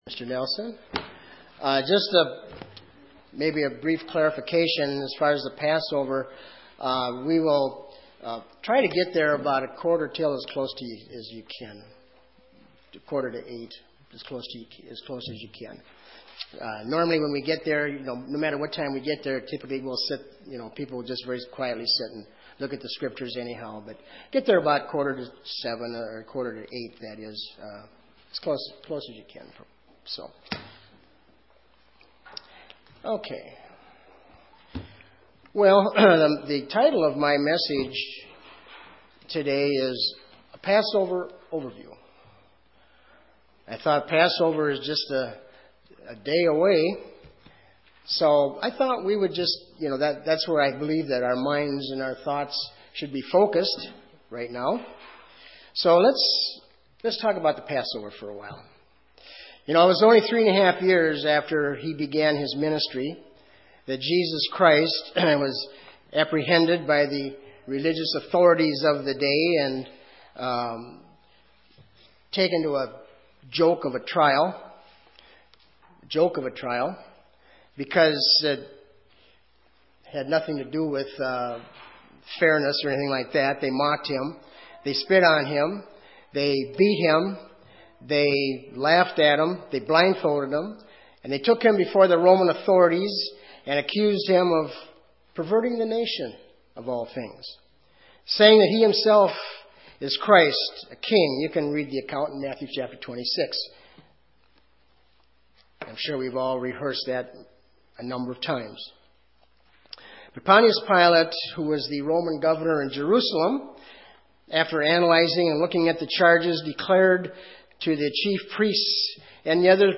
Given in Southern Minnesota
UCG Sermon Studying the bible?